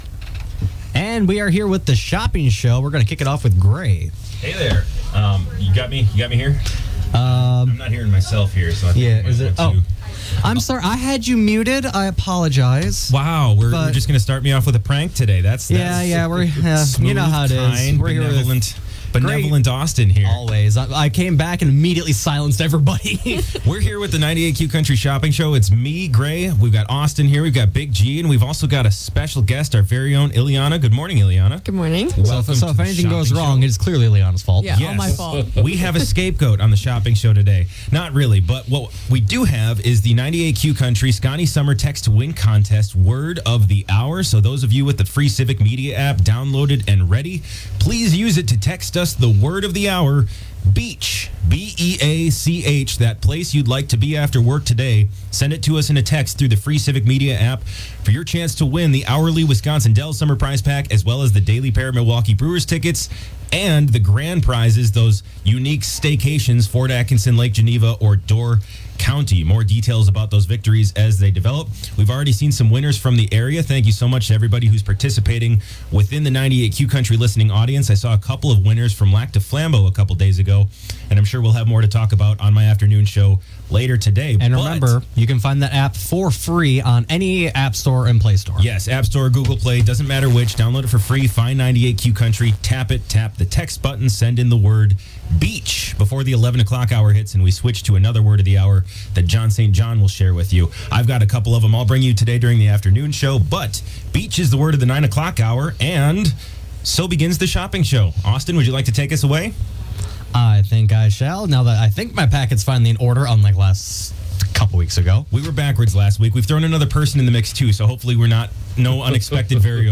Interviews and special broadcasts from 98Q Country in Park Falls.